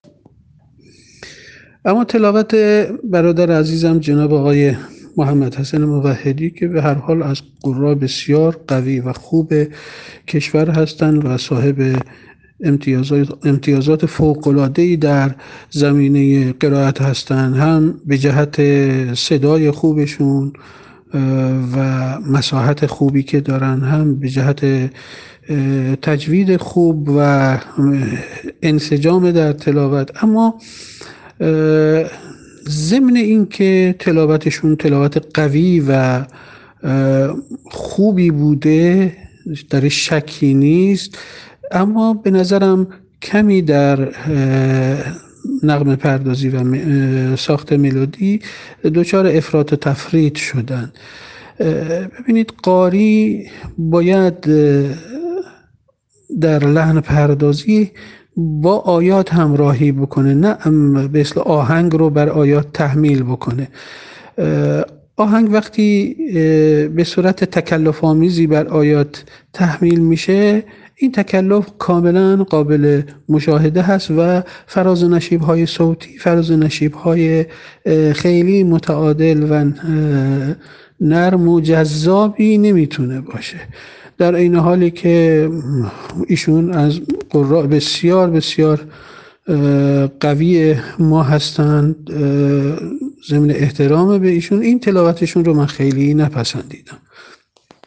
اجرا شده در مرحله فینال چهل و چهارمین دوره مسابقات سراسری قرآن سازمان اوقاف
تحلیل تلاوت:
اما ضمن اینکه تلاوتشان قوی و خوب بود، اما به نظر کمی در نغمه‌پردازی و ساخت ملودی دچار افراط و تفریط شدند. قاری باید در لحن‌پردازی با آیات همراهی کند نه اینکه آهنگ را با آیات تحمیل کند.